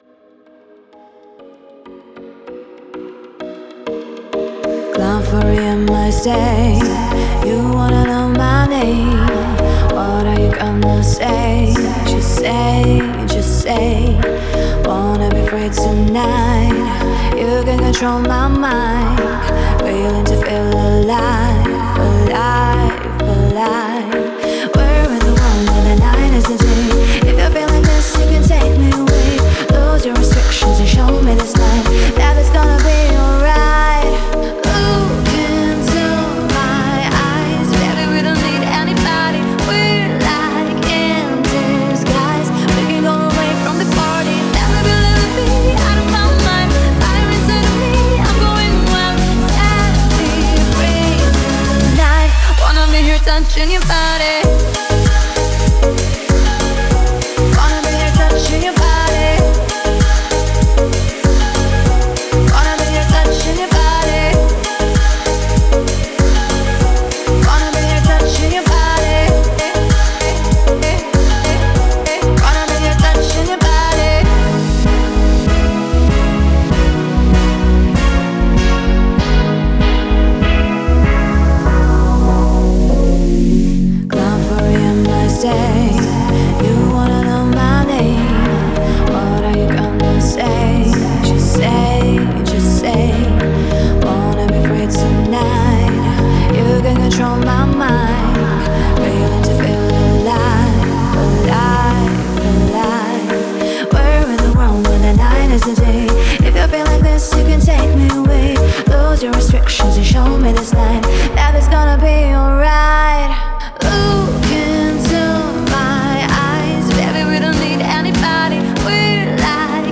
это энергичный трек в жанре электронной музыки